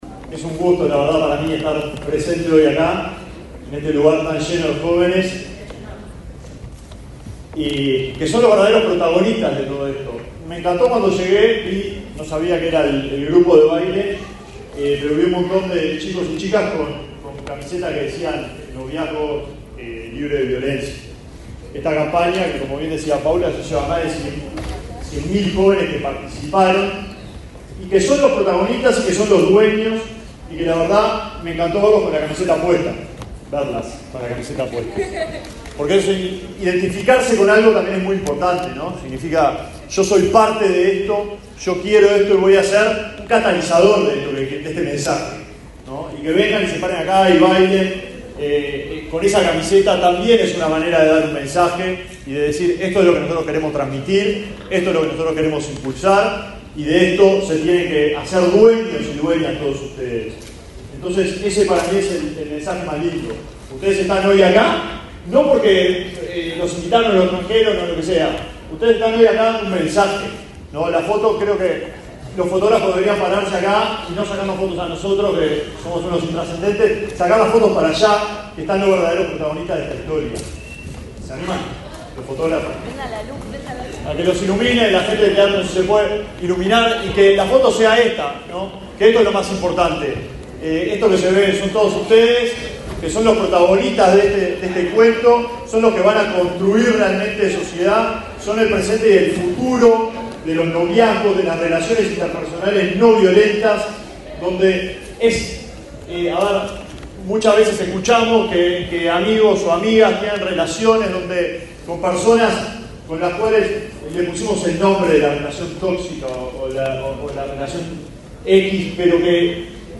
Palabras del ministro de Desarrollo Social, Alejandro Sciarra
Palabras del ministro de Desarrollo Social, Alejandro Sciarra 30/09/2024 Compartir Facebook X Copiar enlace WhatsApp LinkedIn Este lunes 30 en Montevideo, el ministro de Desarrollo Social, Alejandro Sciarra, hizo uso de la palabra durante el acto de cierre de la novena edición de la campaña Noviazgos Libres de Violencia.